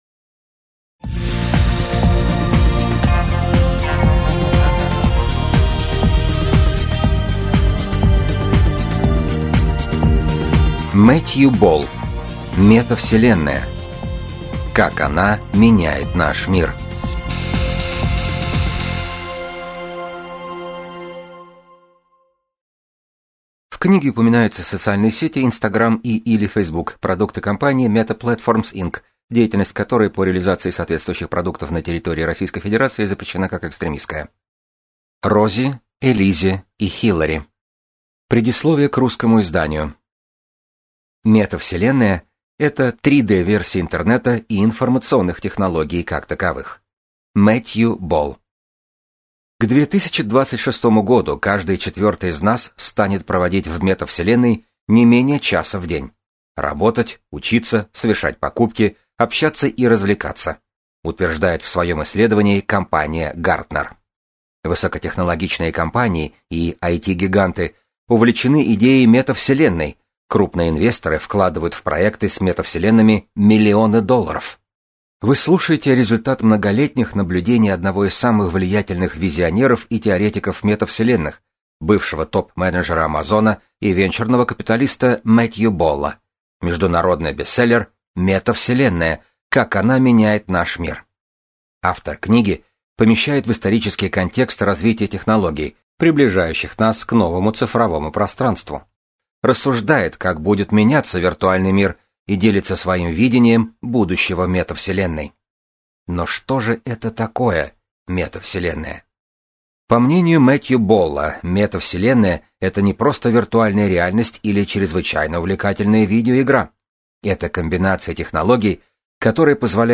Аудиокнига Метавселенная. Как она меняет наш мир | Библиотека аудиокниг